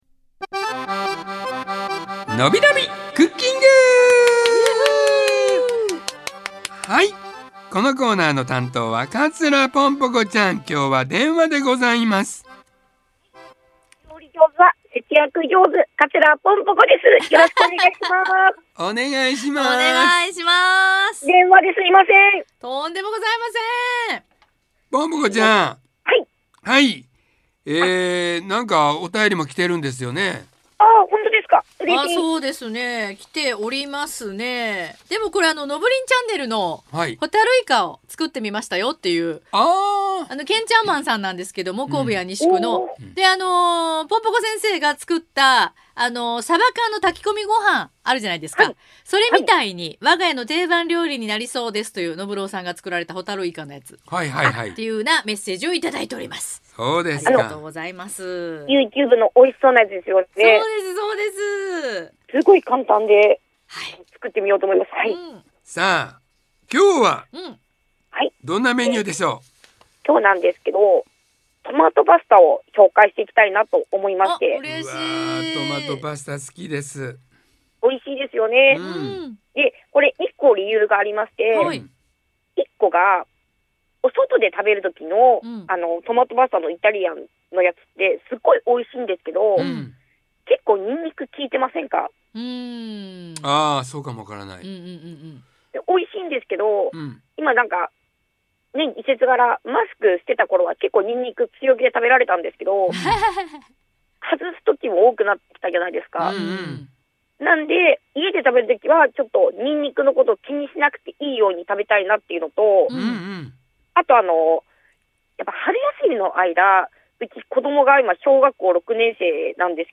【放送音声】2023年4月14日放送回